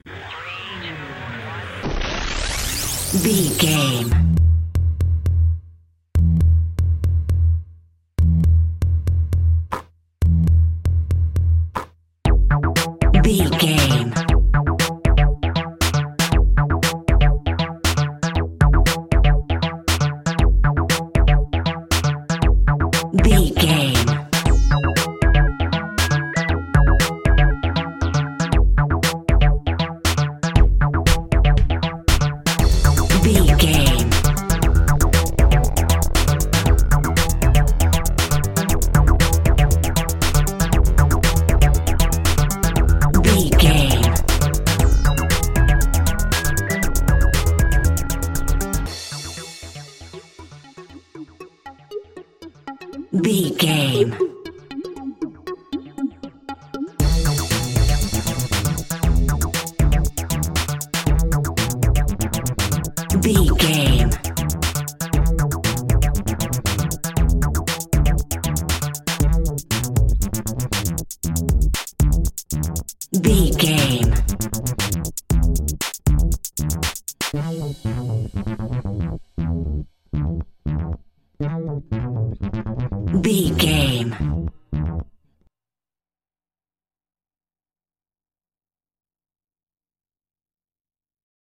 Aeolian/Minor
groovy
smooth
futuristic
drum machine
synthesiser
Drum and bass
break beat
electronic
sub bass
synth leads
synth bass